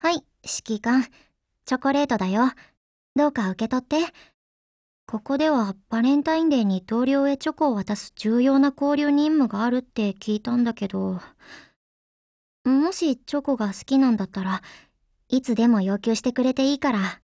贡献 ） 协议：Copyright，其他分类： 分类:少女前线:SP9 、 分类:语音 您不可以覆盖此文件。